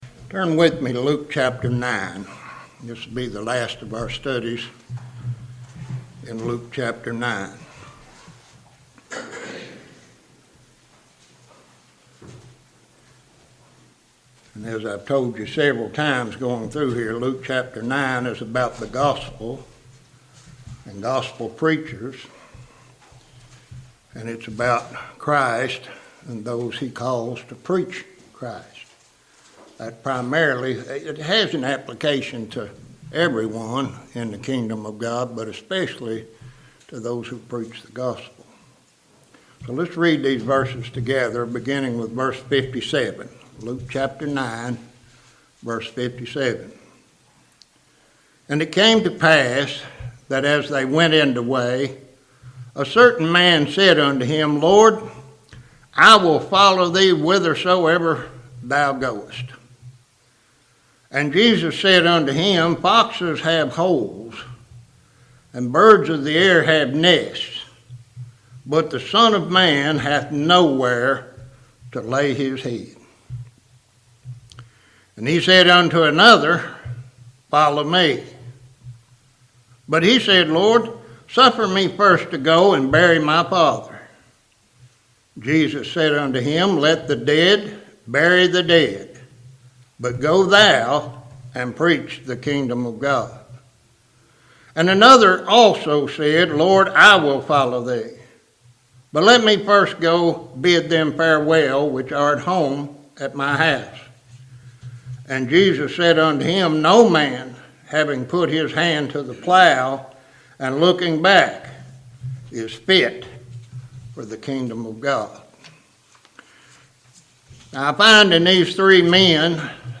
Three Hard Sayings | SermonAudio Broadcaster is Live View the Live Stream Share this sermon Disabled by adblocker Copy URL Copied!